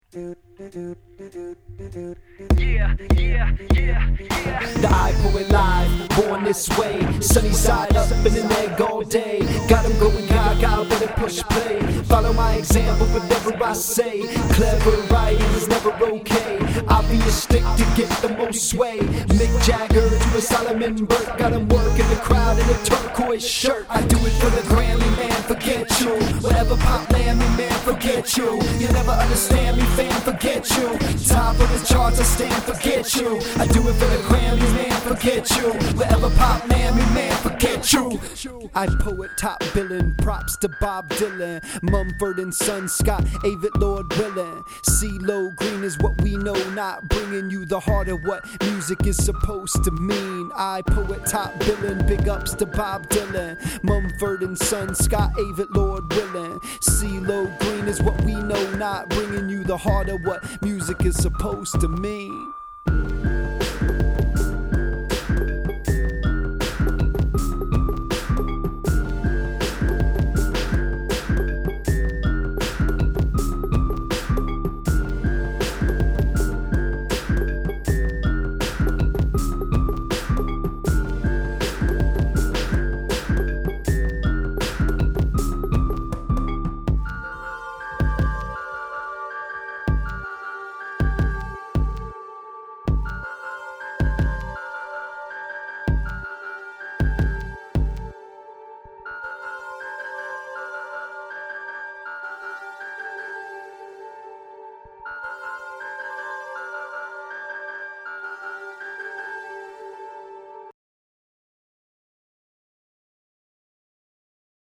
By the way, I recorded today’s audio in a pair of handmade meat pants.
I’m really liking your voice/rhymes/flow over the slower beat in the second half.